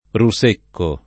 [ ru S% kko ]